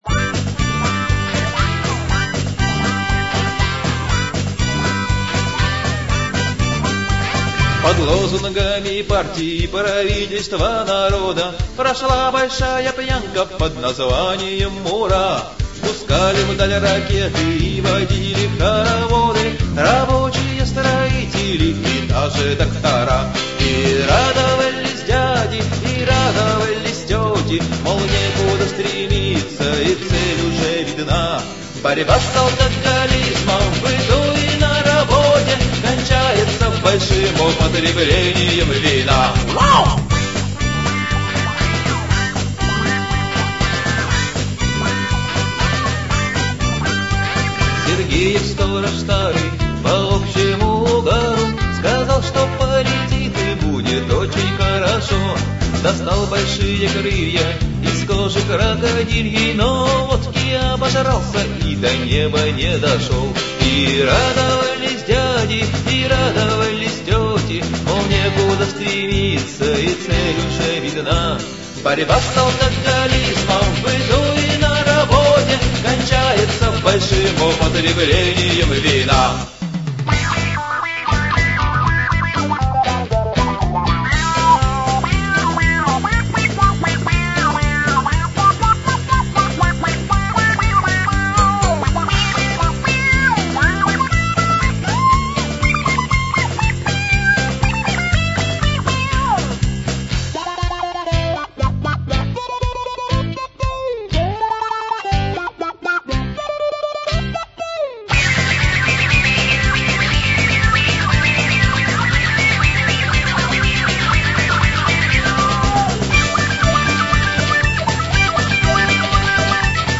• Жанр: Рок
• Жанр: Комедия